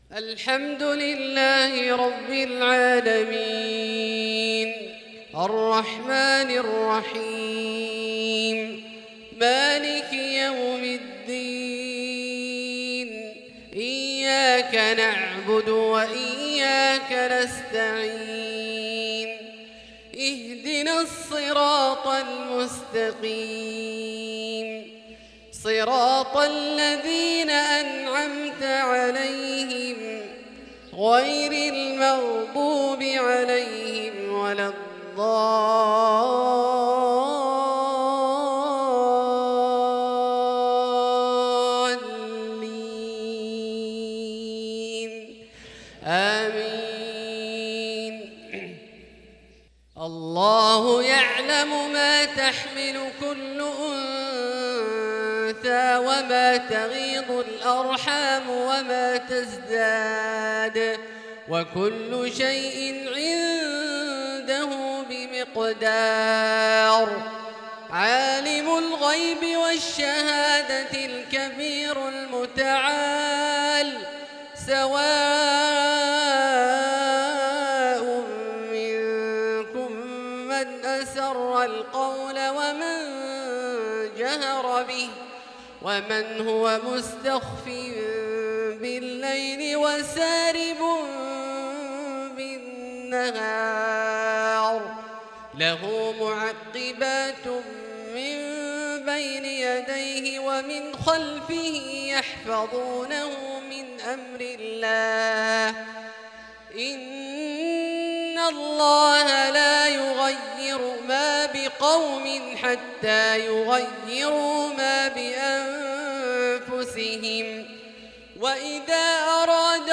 "و ينشئ السحاب الثقال ⛈" أداء رمضاني ندي للشيخ عبدالله الجهني من عشاء 9 جمادى الآخرة 1444هـ